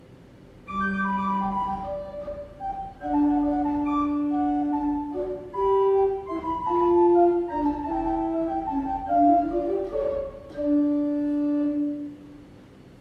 Spitzflöte 4'
Das Instrument befindet sich in gutem Zustand, ist recht mild intoniert und daher für kleine Kirchenräume oder als Haus- und Übeorgel gut verwendbar.